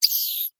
ratDie1.mp3